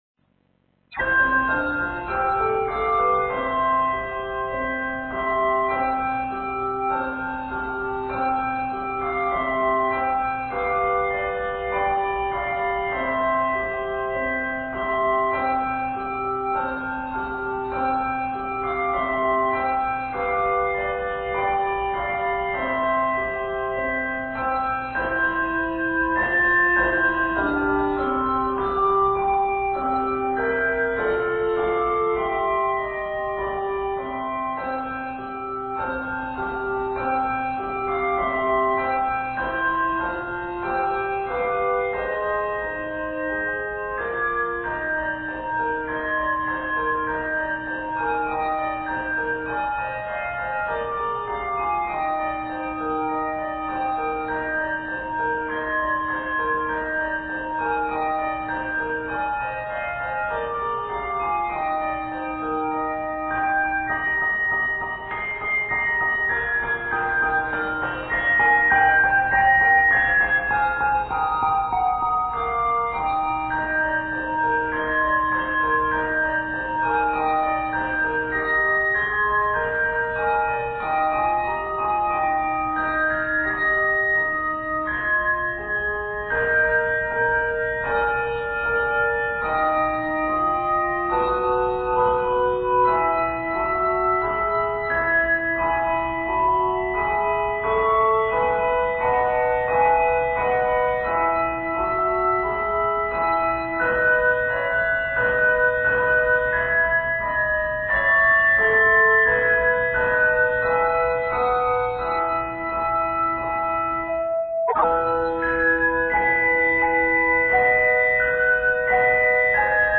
ending with an elegant combining of the 2 hymn melodies.